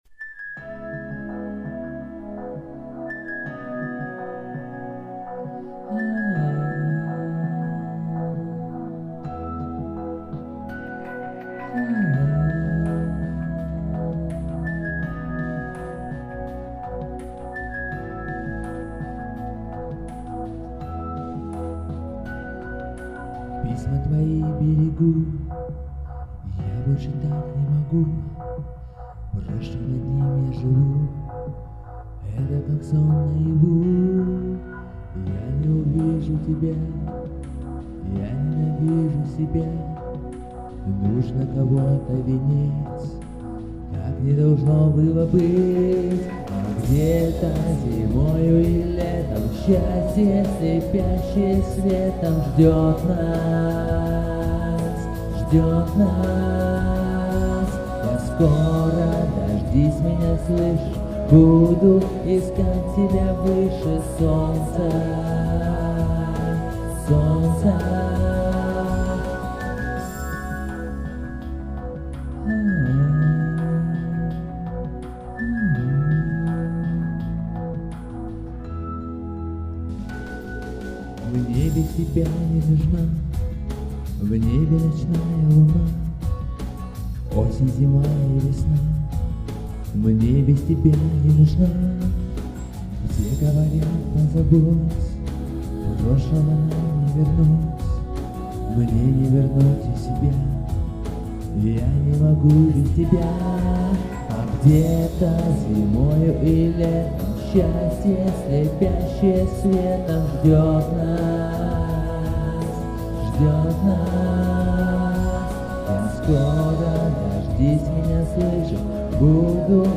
пел в одной комнате а запись шла в другой.